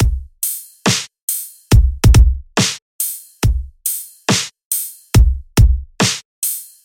简单的Dubstep节拍
描述：简单的Dubstep节拍，踢腿，小鼓和amp; hihat
Tag: 140 bpm Dubstep Loops Drum Loops 1.15 MB wav Key : Unknown